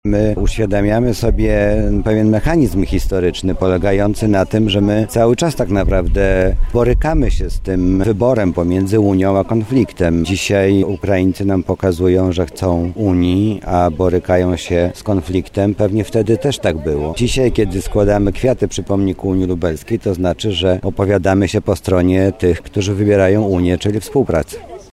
• mówi Mariusz Banach zastępca prezydenta ds. Oświaty i Wychowania.